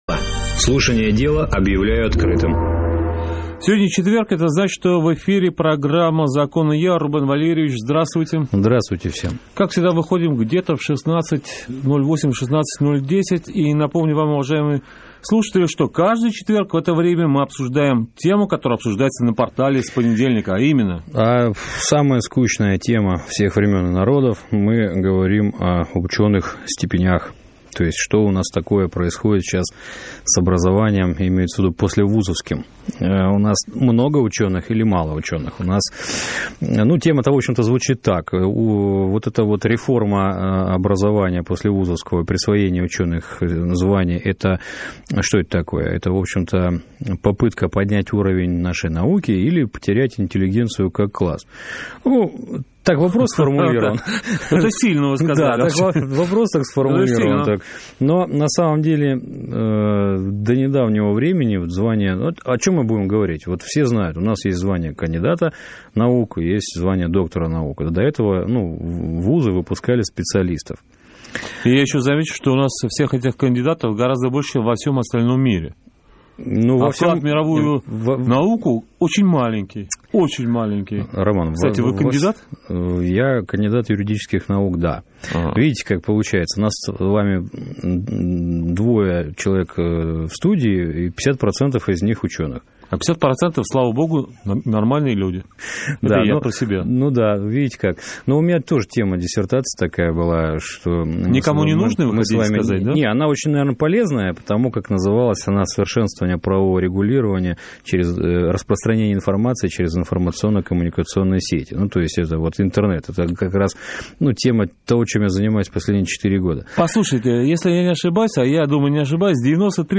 СЛУШАТЬ ЭФИР (08.12.2011) Обсуждение